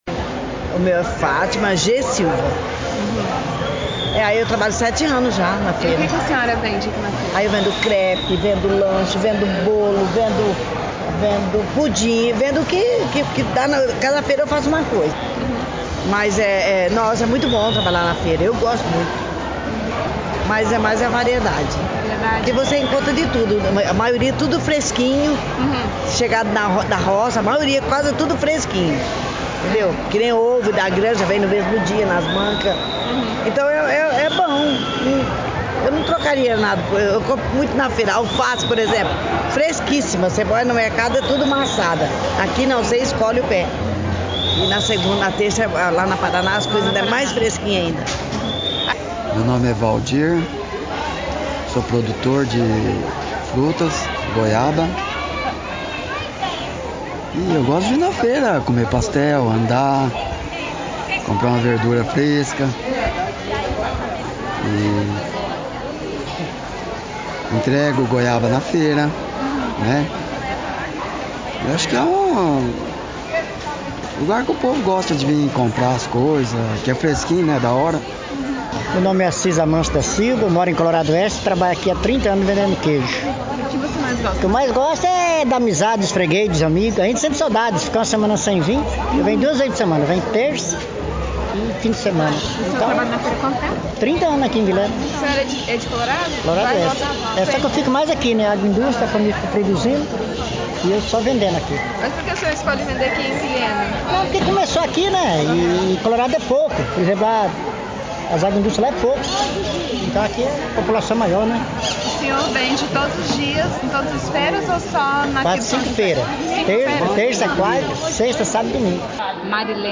Entrevistas-Centro-e-São-José.mp3